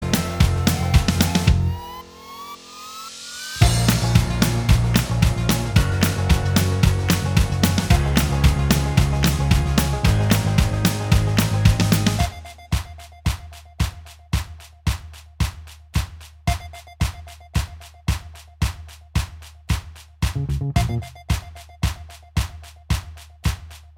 Minus All Guitars Pop (2010s) 3:32 Buy £1.50